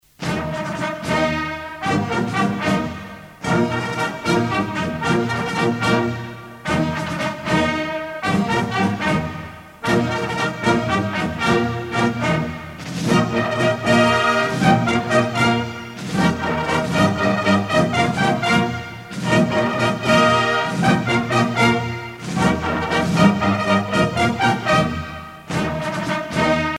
gestuel : à marcher
circonstance : militaire
Pièce musicale éditée